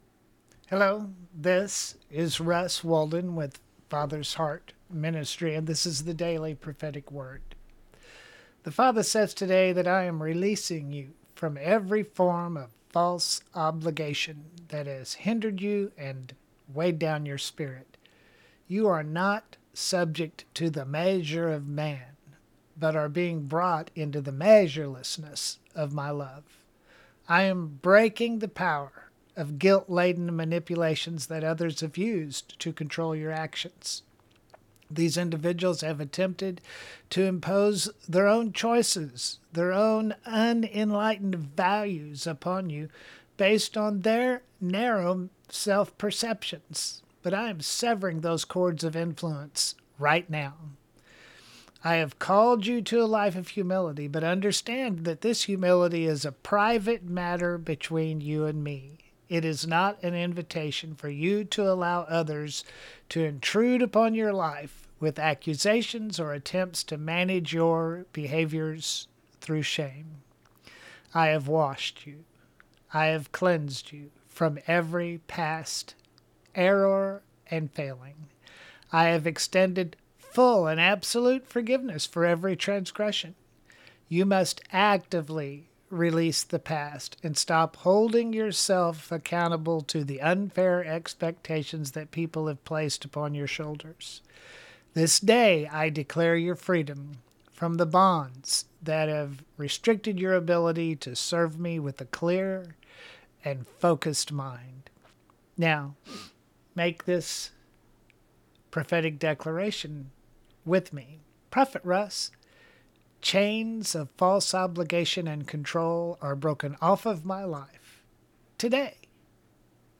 Daily Prophetic Word